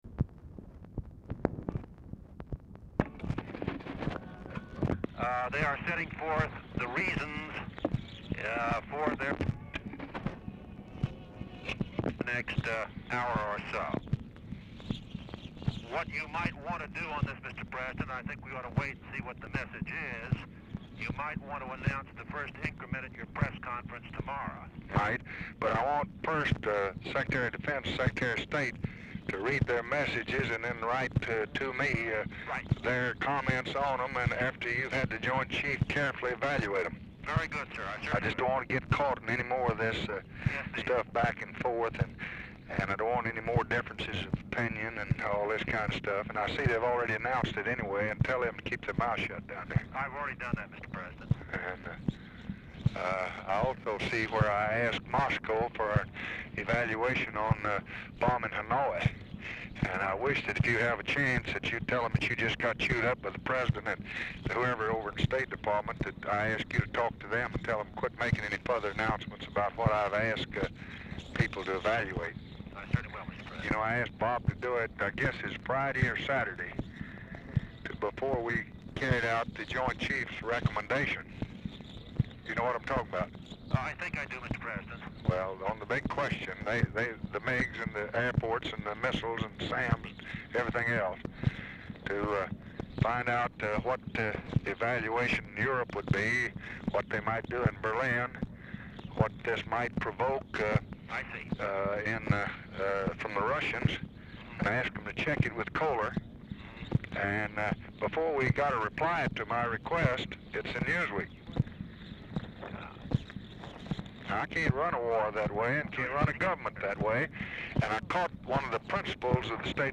RECORDING STARTS AFTER CONVERSATION HAS BEGUN; RECORDING IS INTERRUPTED BRIEFLY AT BEGINNING OF CALL
Format Dictation belt
Location Of Speaker 1 LBJ Ranch, near Stonewall, Texas
Specific Item Type Telephone conversation